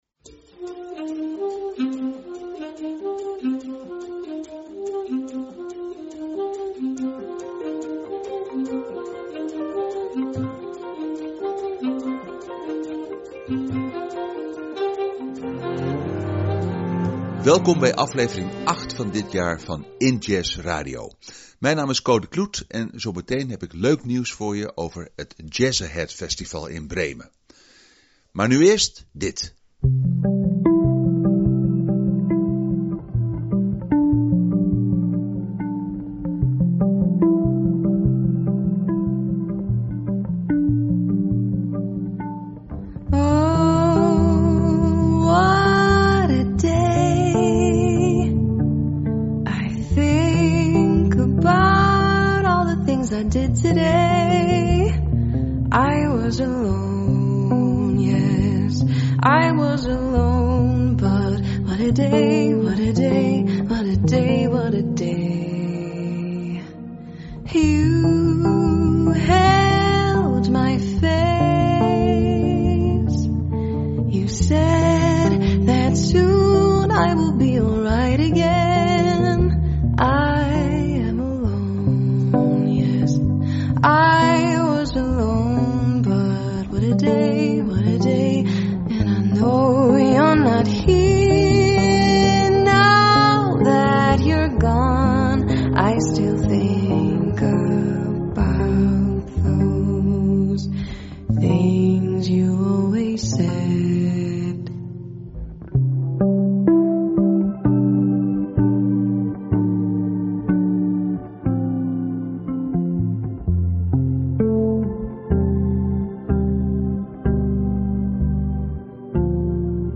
InJazz Radio maakt zelf liveopnames op locatie, zendt daarnaast opnames uit gemaakt tijdens de inJazz festivalavonden en andere evenementen rond Nederlandse, jazz- en globalgerelateerde muziek.